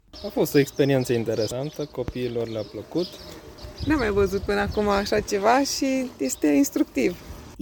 Pentru prima oară a avut loc și o inelare într-un cuib de oraș, la Tîrgu Mureș. Motiv pentru care, ornitologii au realizat o sesiune deschisă pentru iubitorii de natură din oraș.